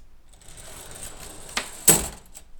Schiebetür
Ein eigenartiges Geräusch, das man aber sofort mir einer Schiebetür in Verbindung bringt. Eigentlich relativ laut, wird das öffnen einer Schiebetür aber nicht wirklich als störend empfunden.
schiebetuer